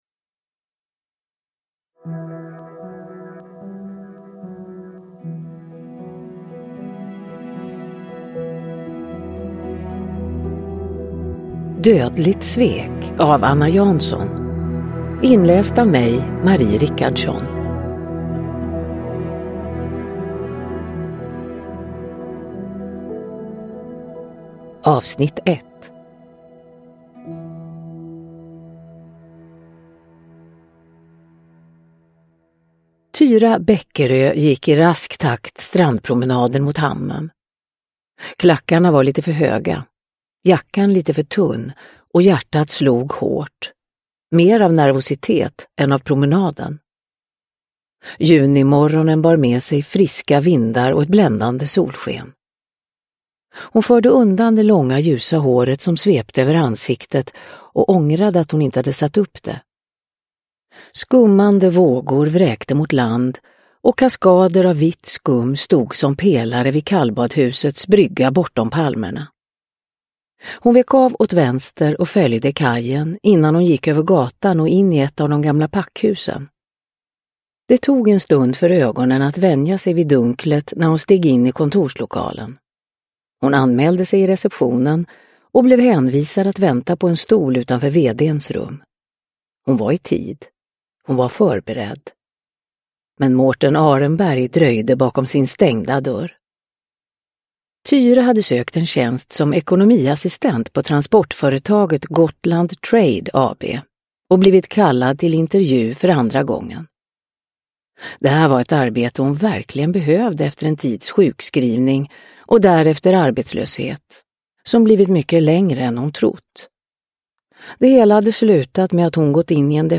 Dödligt svek - 1 – Ljudbok – Laddas ner
Uppläsare: Marie Richardson